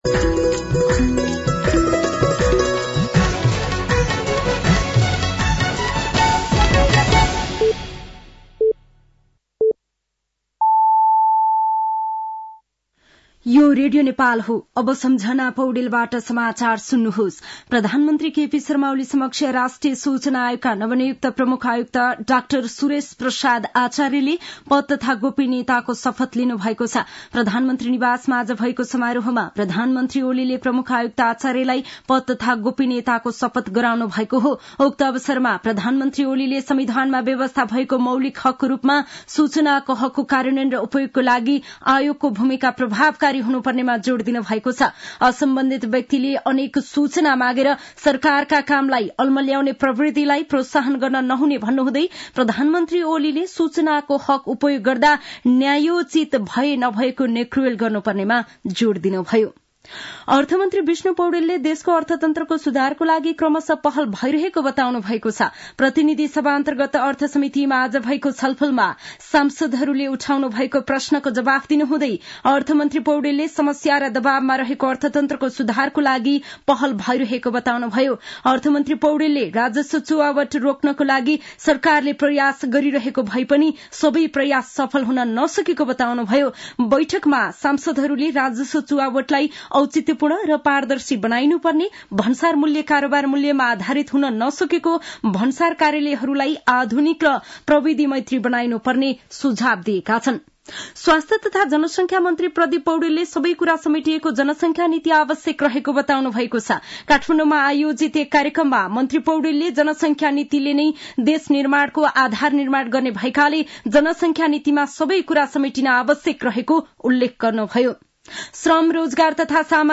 साँझ ५ बजेको नेपाली समाचार : २७ मंसिर , २०८१
5-PM-Nepali-News-8-26.mp3